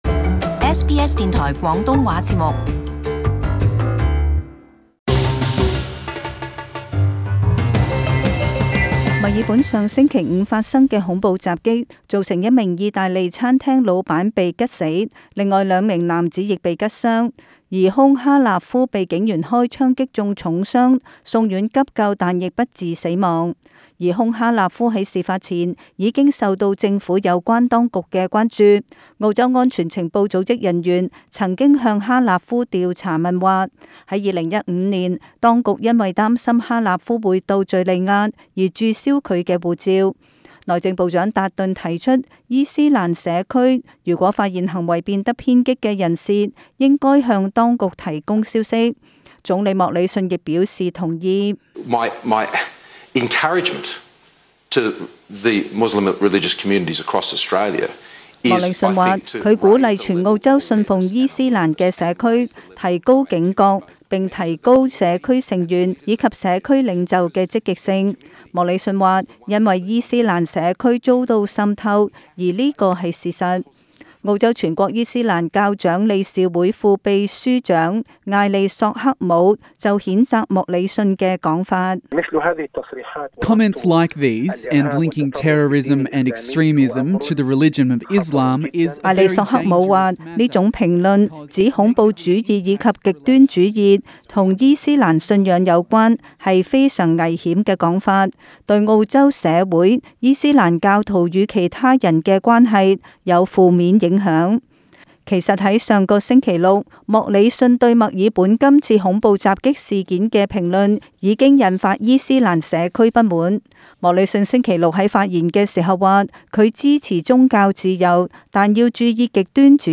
【時事報導】莫理遜恐襲評論引發不滿